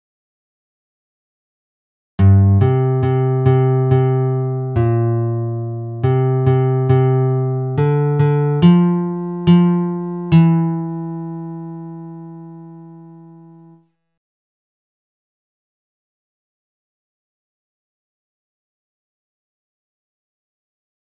Key written in: C Major
Type: Other male
Each recording below is single part only.